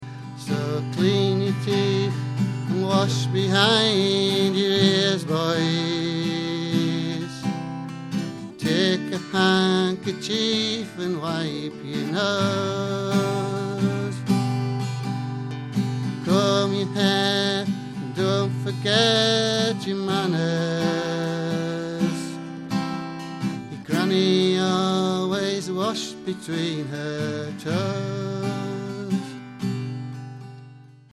Ashington Folk Club - 01 February 2007